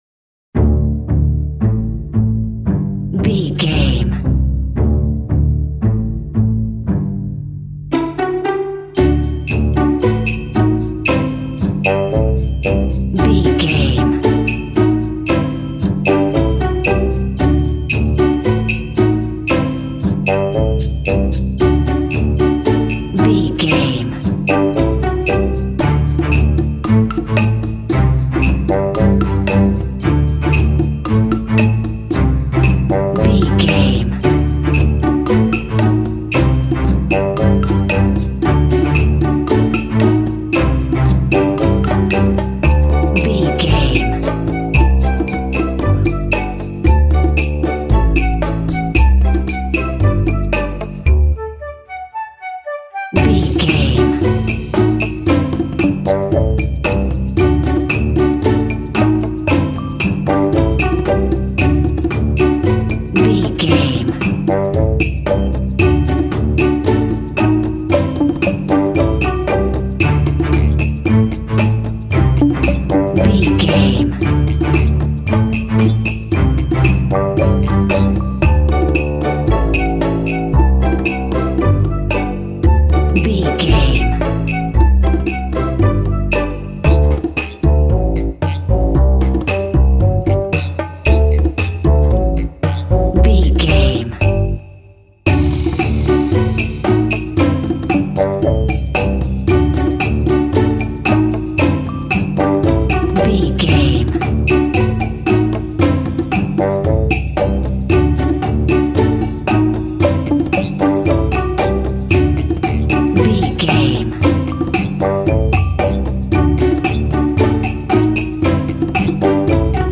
Uplifting
Mixolydian
D
funny
playful
foreboding
suspense
strings
percussion
contemporary underscore